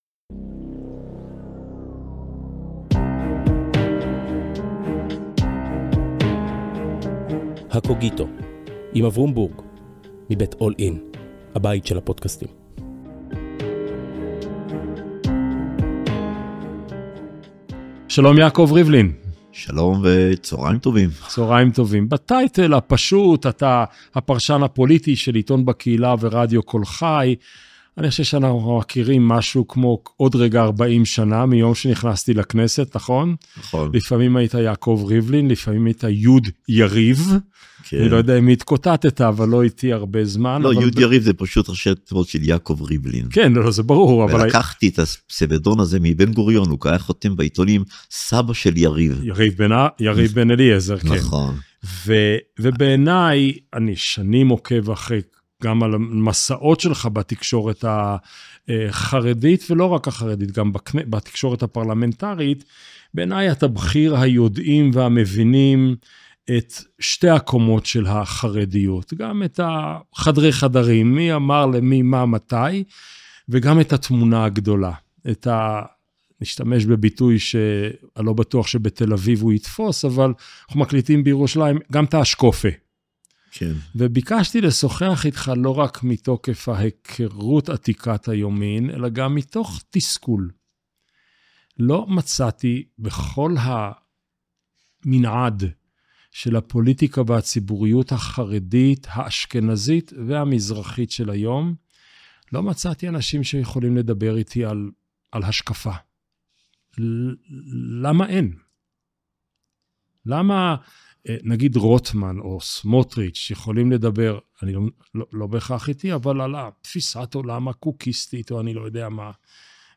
לכל איש ואישה יש טקסט, עליו נבנים המון מגדלי חיים. בפודקסט שבועי משוחח אברום בורג עם דמות מובילה אחרת בשדה התרבות והרוח על הטקסט המכונן של חייה. שיחה לא שיפוטית, קשובה אבל מאתגרת.